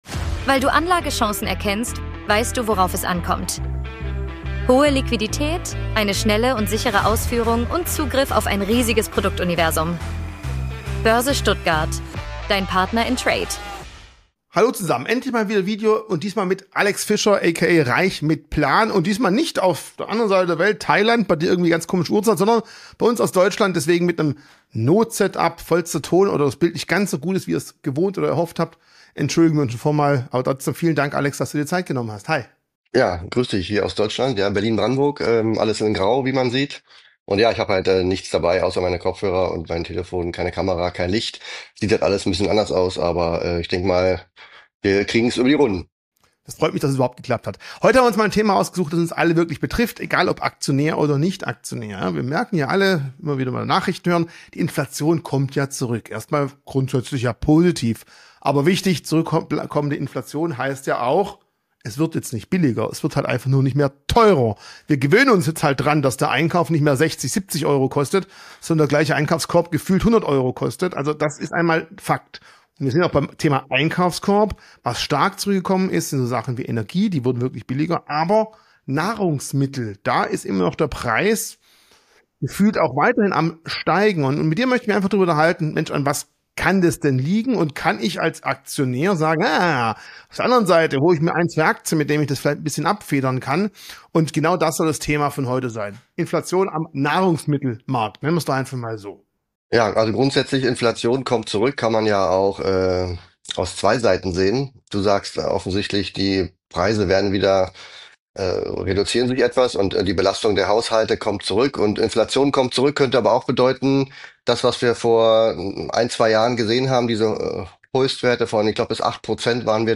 Invest 2024